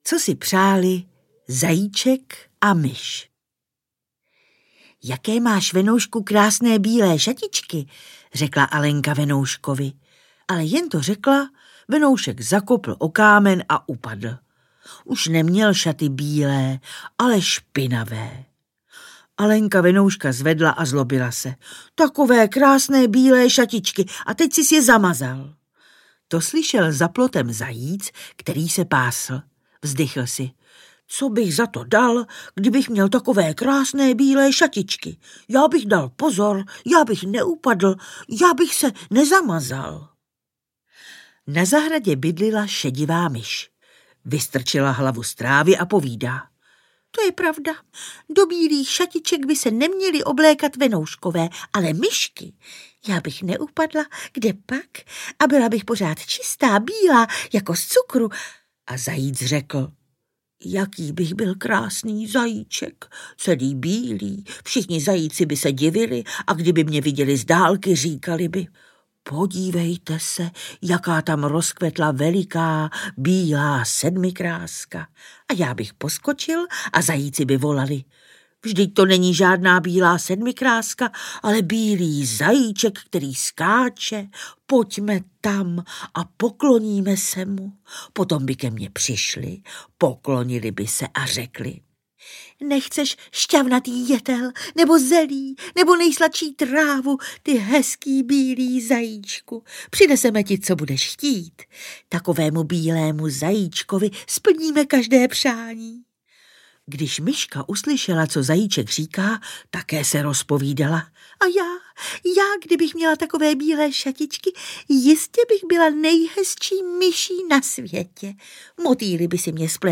Martínkova čítanka audiokniha
Ukázka z knihy
Vyprávění se ujala herečka Naďa Konvalinková.